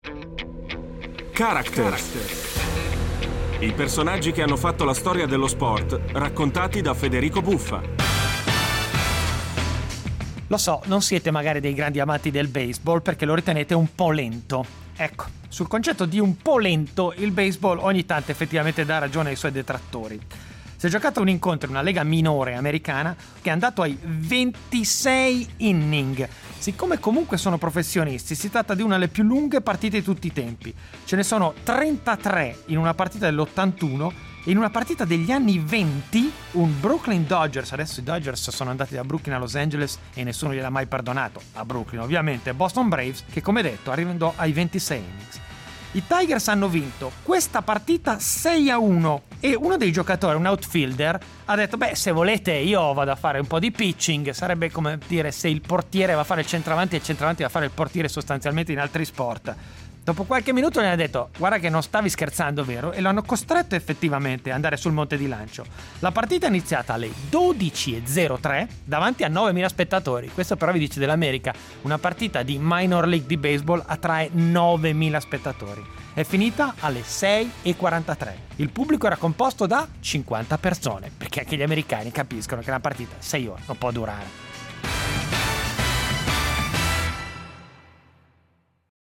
Con Federico Buffa
La partita più lunga nella storia della Major League, durata ben 26 inning e disputatasi il 1° maggio 1920, raccontata da Federico Buffa.